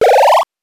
powerup_12.wav